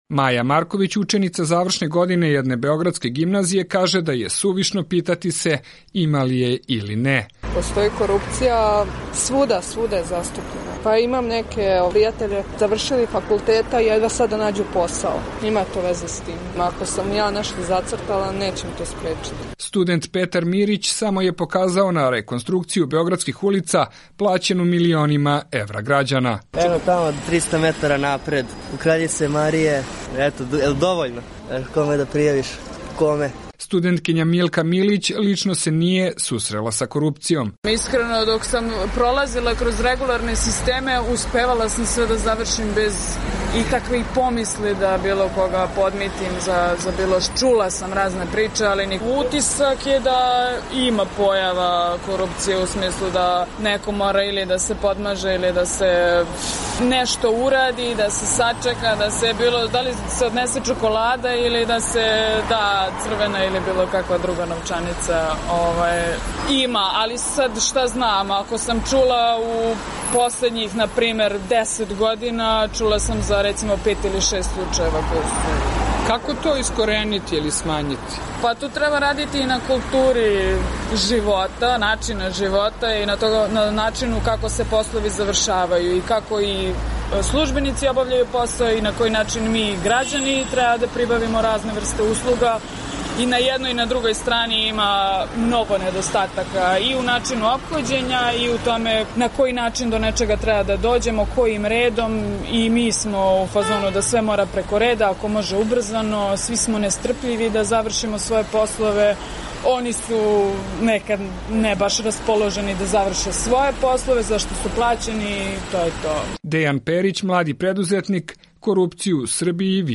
U ovosedmičnoj emisiji "Glasom mladih" pitali smo mlade iz Srbije, Crne Gore i BiH da li i koliko korupcija utiče na kvalitet njihovog života, ali i ostaviravanje životnih ciljeva?
Korupcija u Srbiji je rasprostranjena, zbog čega je teže ostvariti uobičajene ciljeve nakon školovanja, kaže većina mladih Beograđana